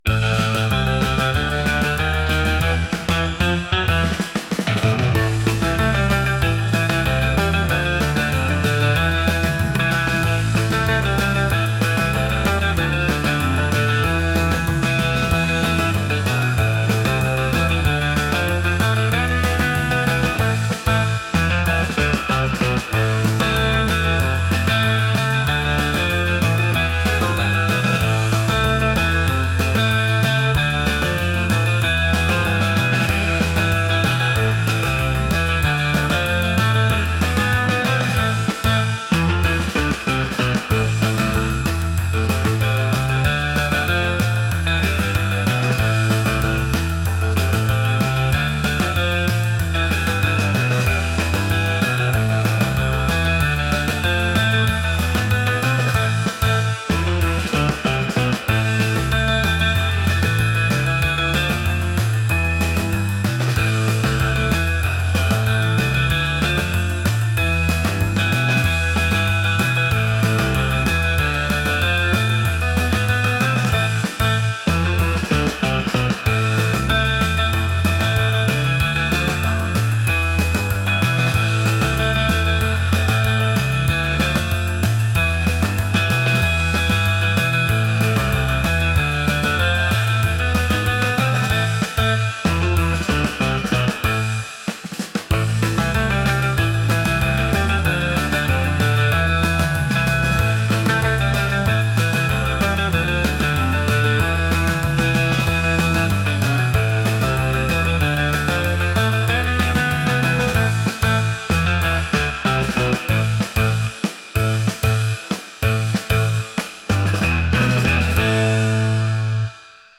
energetic | vibes | rock